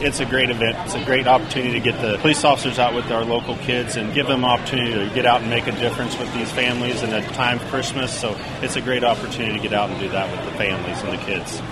Sheriff Jager says the event is also a good opportunity for officers to interact with local families.